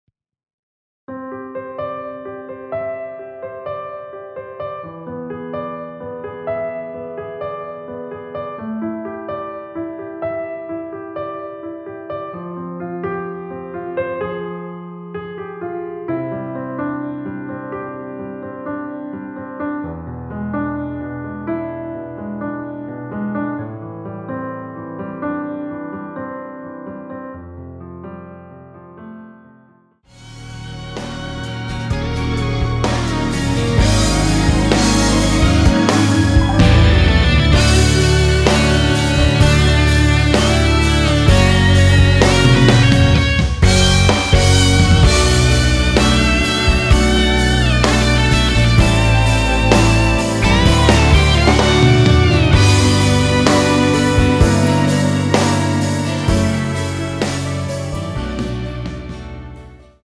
원키에서(-2)내린 MR입니다.
앞부분30초, 뒷부분30초씩 편집해서 올려 드리고 있습니다.